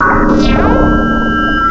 cry_not_spiritomb.aif